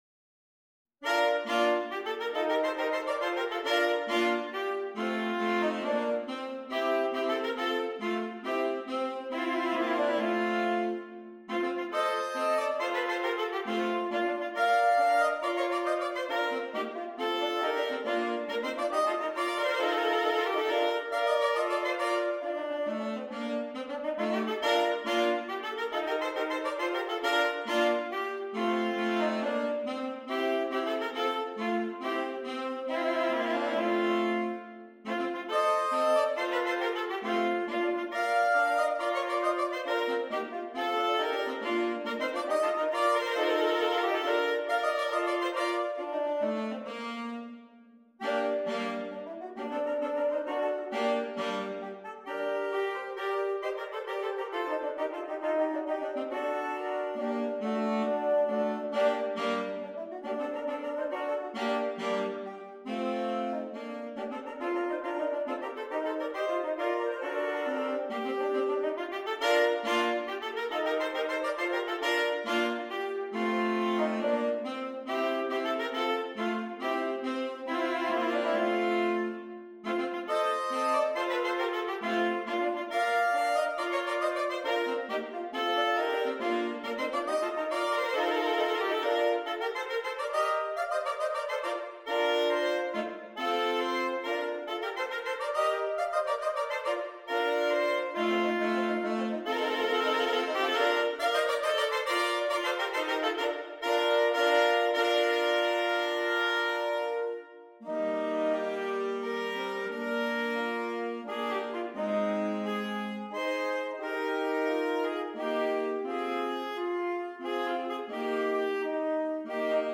3 Alto Saxophones